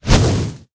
fireball4.ogg